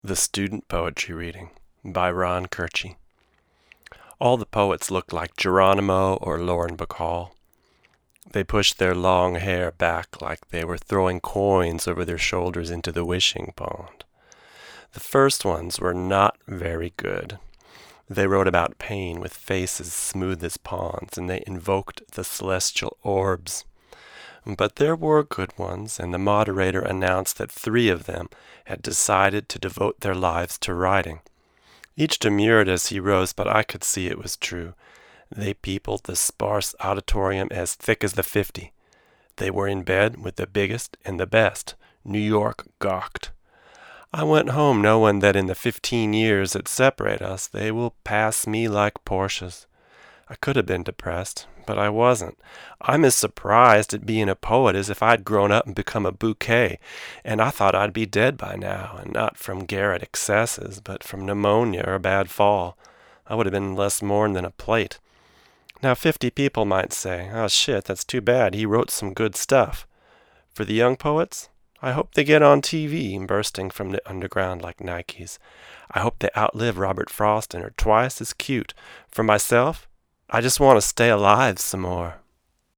It has bass roll-off to compensate for proximity effect, and a slight HF bump.
Here are a few quick, 1-take MP3 sound files to give you an idea of what to expect. These MP3 files have no compression, EQ or reverb -- just straight signal, tracked into a Presonus ADL 600 preamp to an Apogee Rosetta 200 A/D converter into Logic.
VOICE OVER